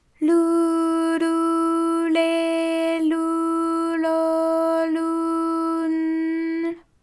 ALYS-DB-001-JPN - First Japanese UTAU vocal library of ALYS.
lu_lu_le_lu_lo_lu_n_l.wav